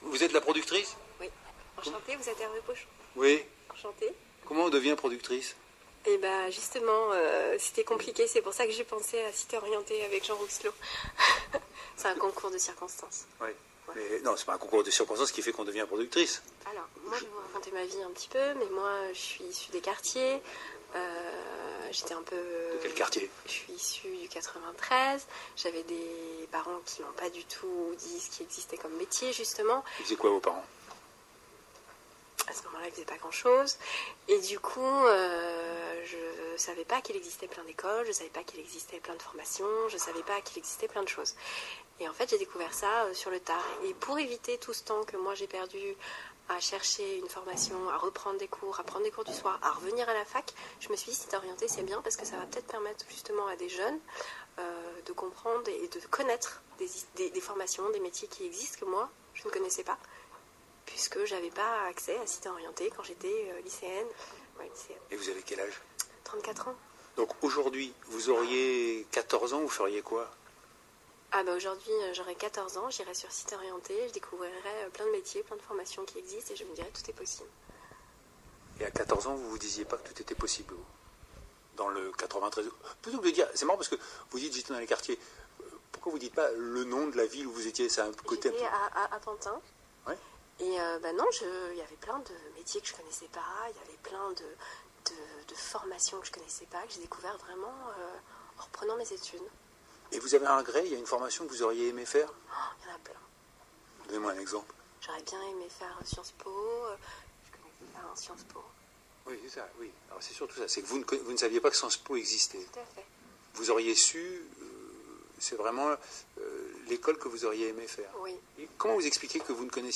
Jeune productrice